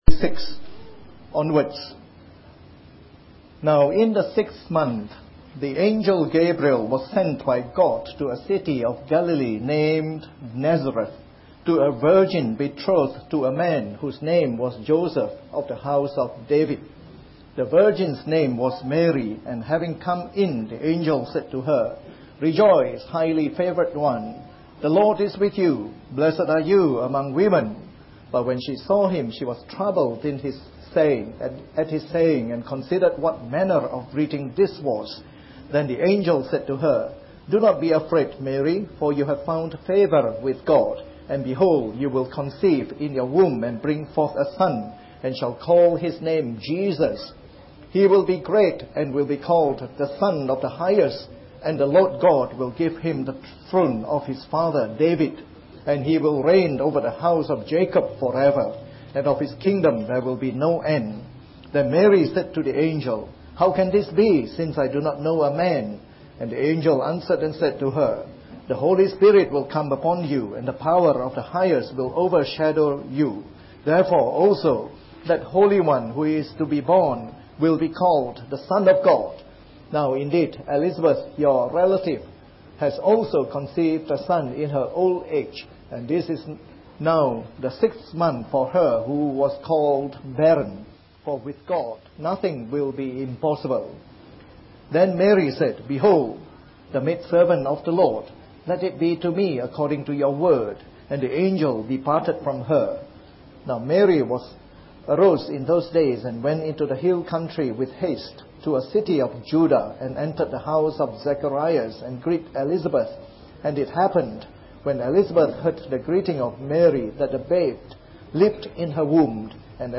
This message was preached on Christmas Day during our yearly Christmas service.